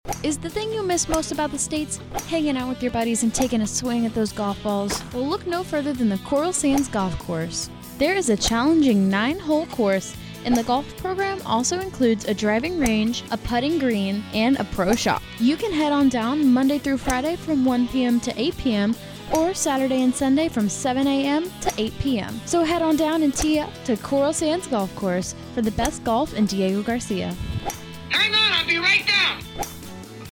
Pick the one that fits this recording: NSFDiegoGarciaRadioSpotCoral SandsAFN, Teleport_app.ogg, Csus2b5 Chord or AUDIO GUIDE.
NSFDiegoGarciaRadioSpotCoral SandsAFN